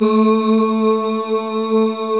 Index of /90_sSampleCDs/Club-50 - Foundations Roland/VOX_xMaleOoz&Ahz/VOX_xMale Ooz 1M